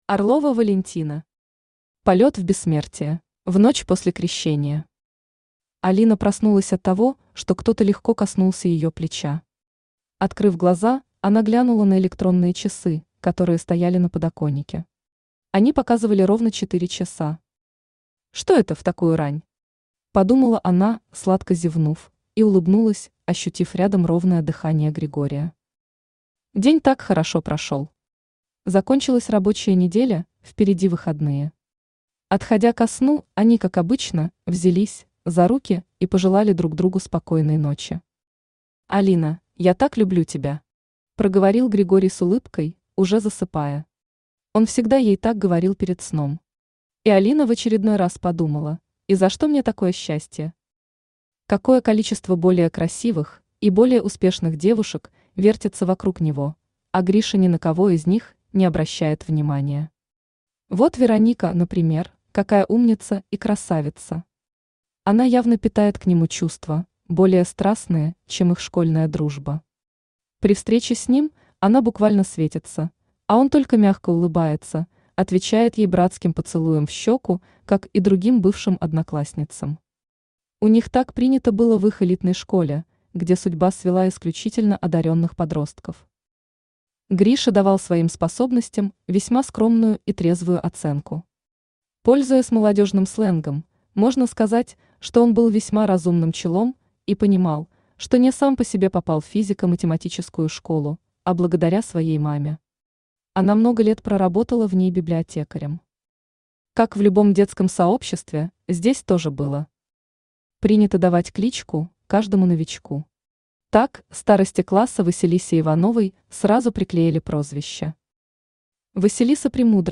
Aудиокнига Полёт в бессмертие Автор Орлова Валентина Читает аудиокнигу Авточтец ЛитРес.